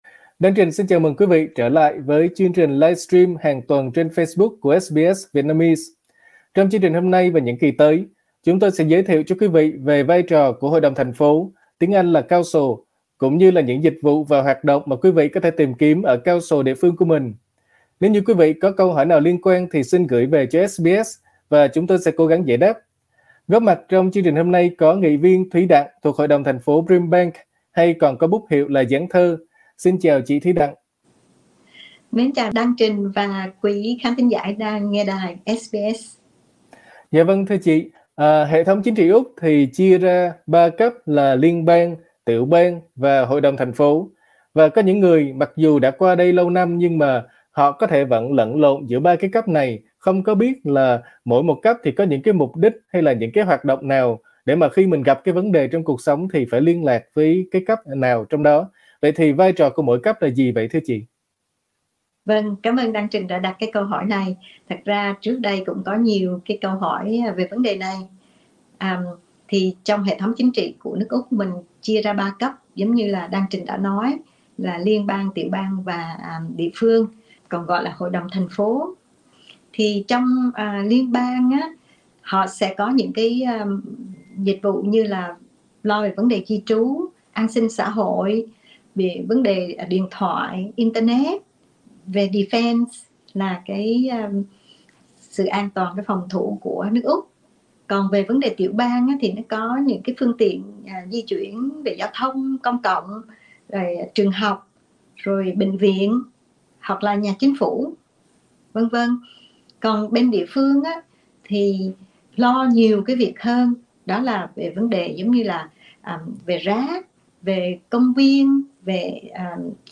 SBS Vietnamese trò chuyện với nghị viên Thuý Đặng đến từ hội đồng thành phố Brimbank để tìm hiểu thêm.